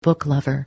But if we combine two nouns into a compound in which the second does something to the first, then we accentuate only the first noun.
BOOK lover (lover of books)